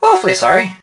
barley_kill_01.ogg